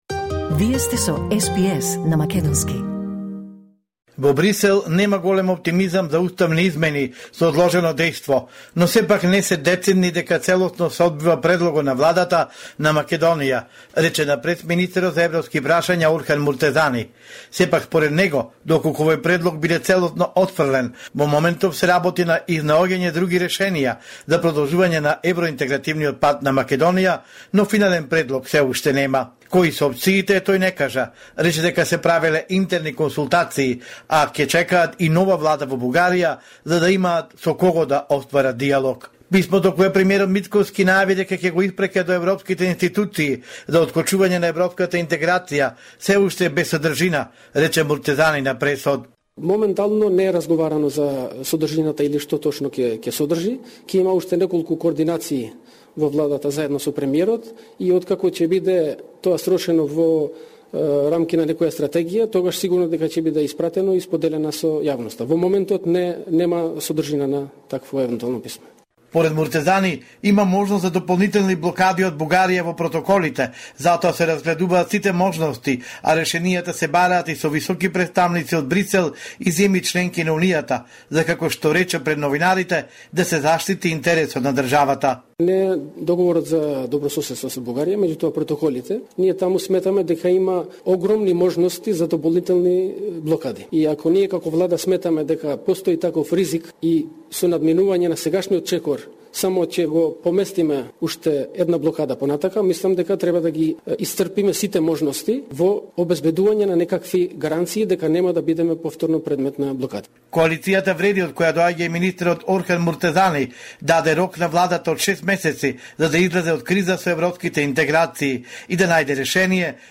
Homeland Report in Macedonian 9 October 2024